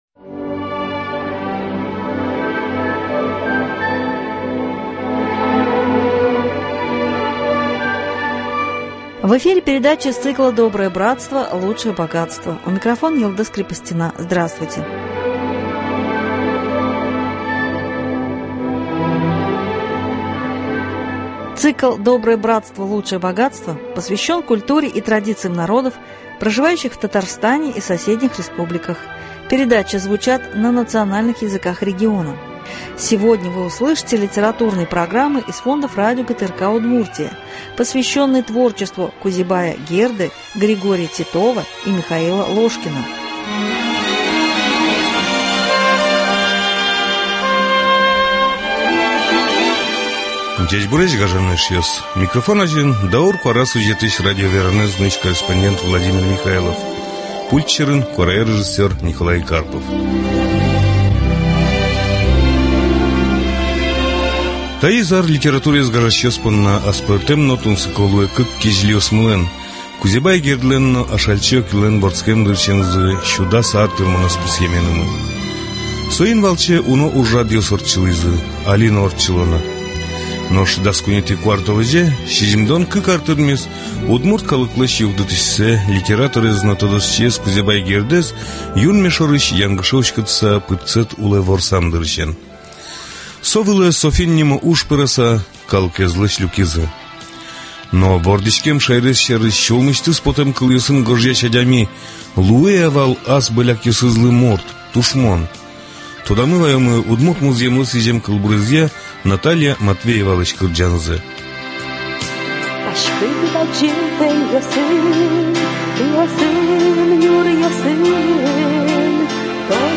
Цикл посвящен культуре и традициям народов, проживающих в Татарстане и соседних республиках, передачи звучат на национальных языках региона . Сегодня вы услышите литературные программы на удмуртском языке из фондов радио ГТРК Удмуртия, посвященные творчеству Кузебая Герды, Григория Титова, Михаила Ложкина.